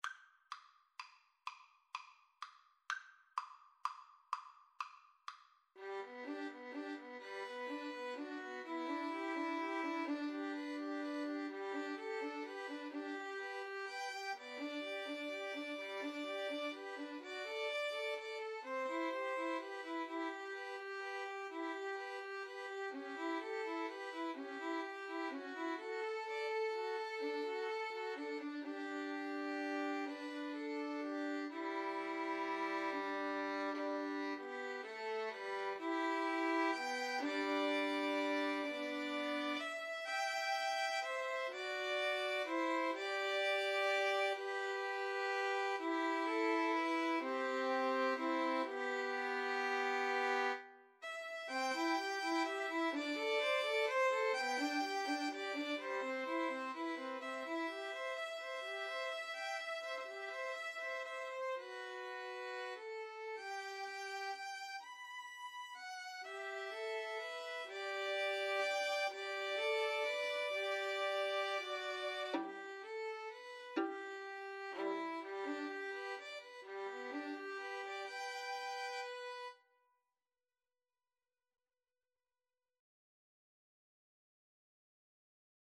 Violin 1Violin 2Cello
G major (Sounding Pitch) (View more G major Music for 2-Violins-Cello )
6/8 (View more 6/8 Music)
Andante ingueno .=42
Classical (View more Classical 2-Violins-Cello Music)
puccini_beloved_father_2VNVC_kar3.mp3